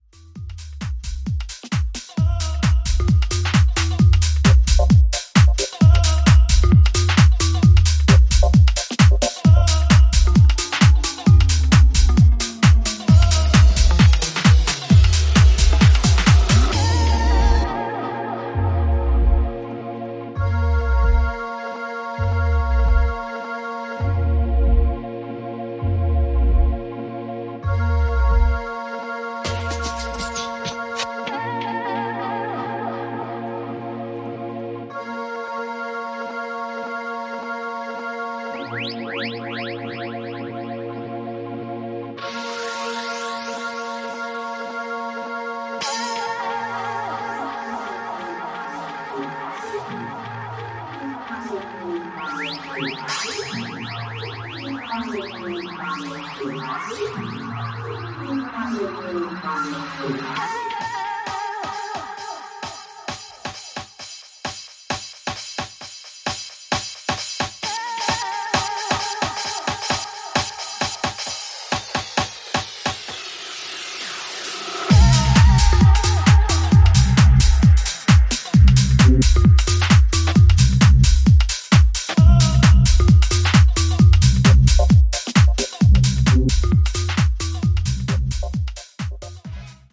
重厚なベースラインでフロアを制圧するUKガラージ以降のピークタイム・トラックスを展開しており